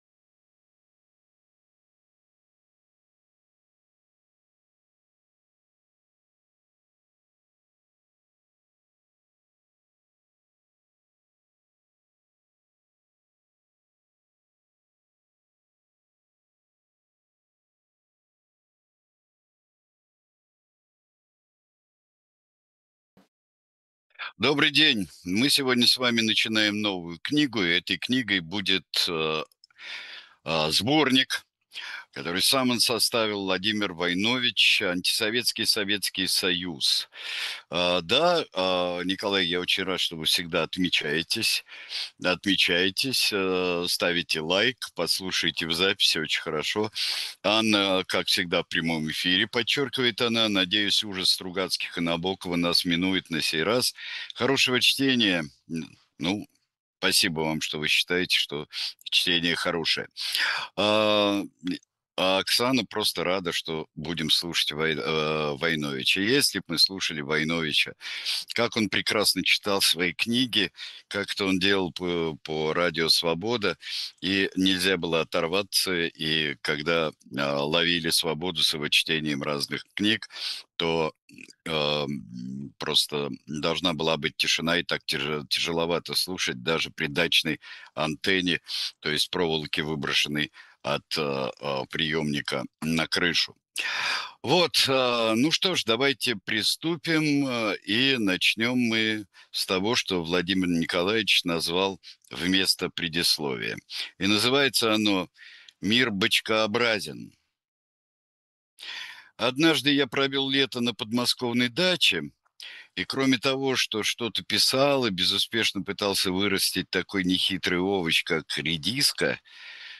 Читает Сергей Бунтман